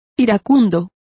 Complete with pronunciation of the translation of irate.